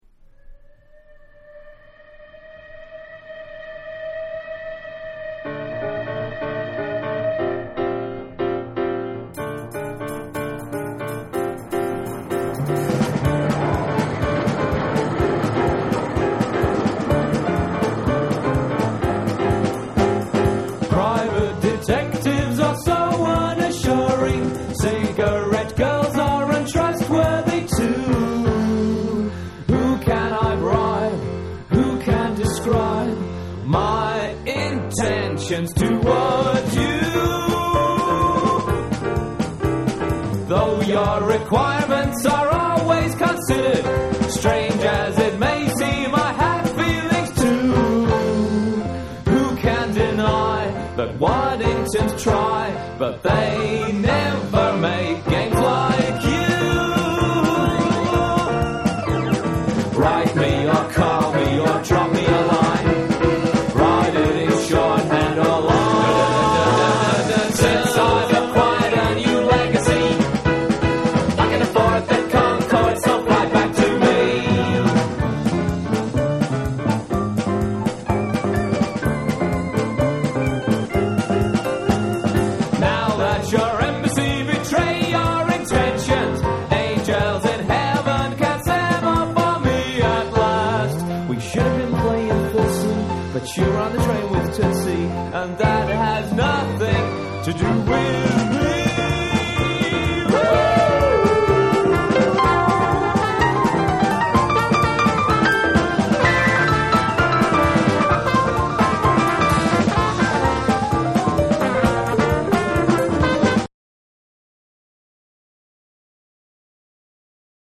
レゲエのリズムを取り入れたライト・メロウ・グルーヴ